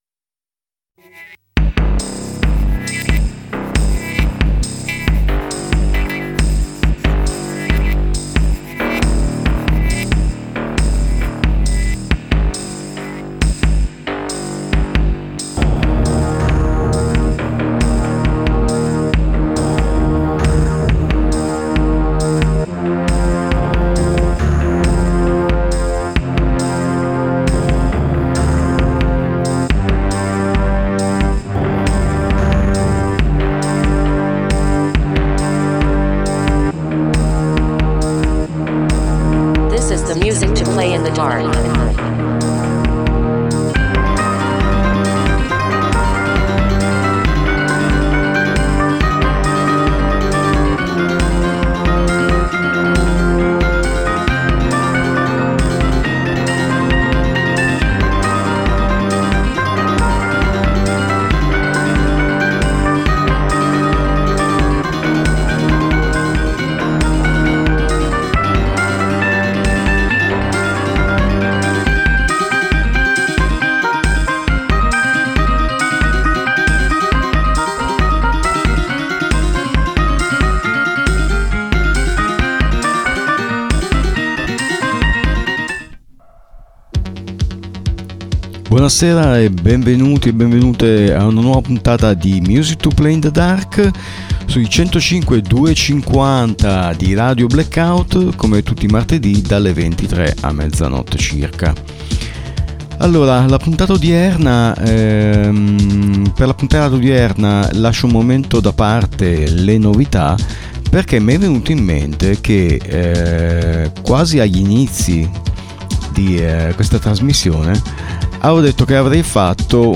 80s Minimal Synth special